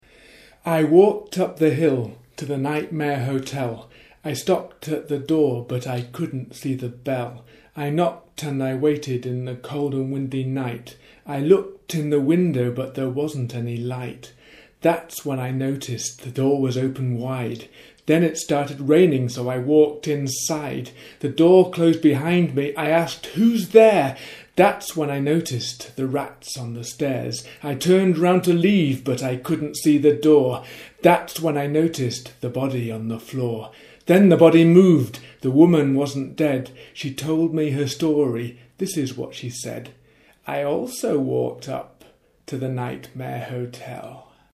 Nightmare Hotel poem.MP3